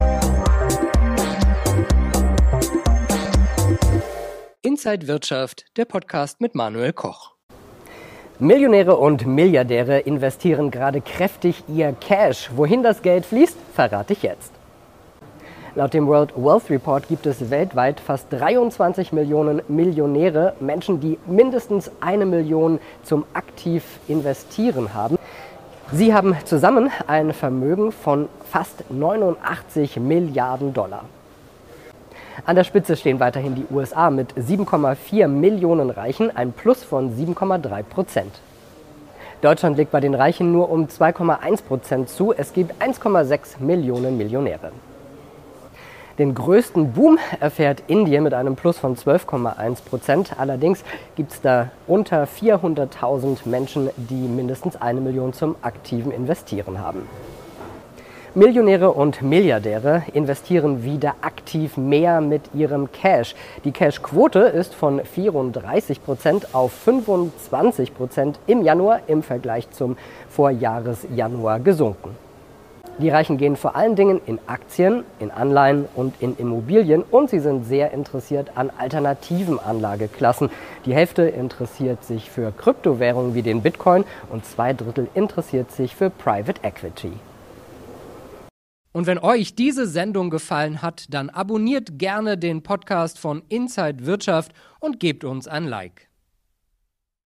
Details im Interview von der Frankfurter Börse und auf